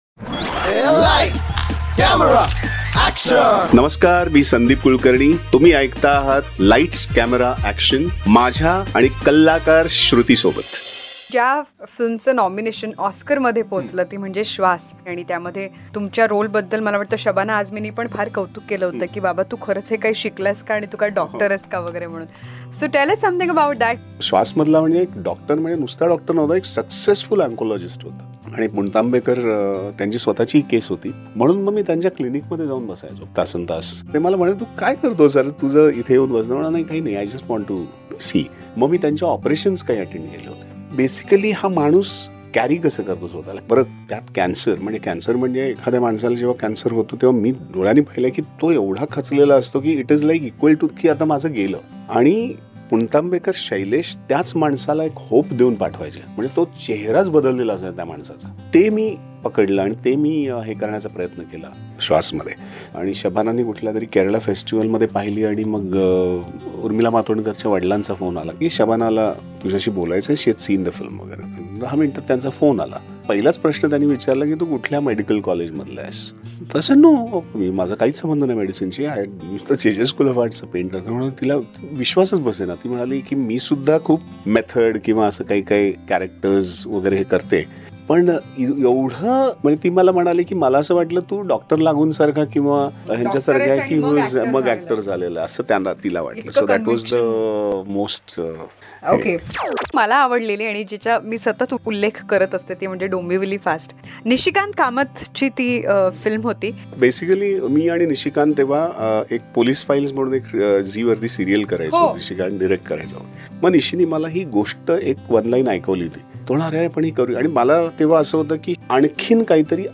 CONVERSATION WITH DOMBIVALI FAST FAME ACTOR SANDEEP KULKARNI PART 5